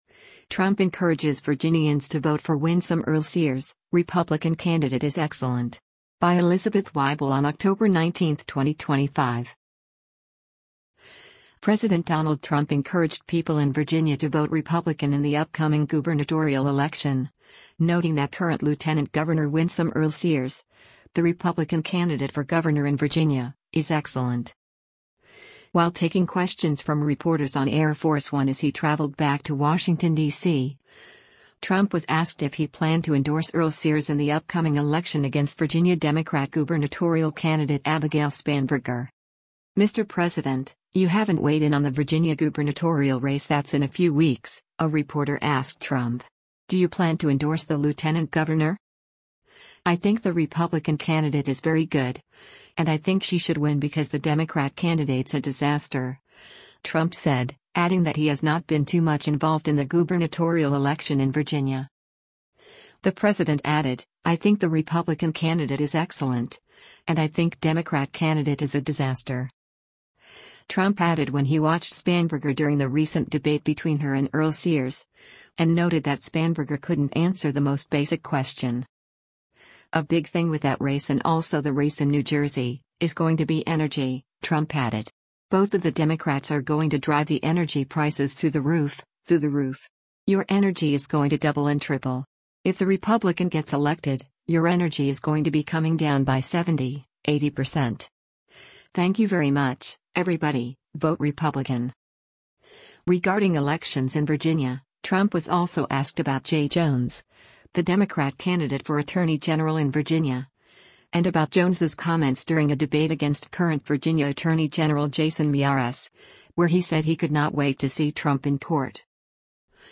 While taking questions from reporters on Air Force One as he traveled back to Washington, DC, Trump was asked if he planned to endorse Earle-Sears in the upcoming election against Virginia Democrat gubernatorial candidate Abigail Spanberger.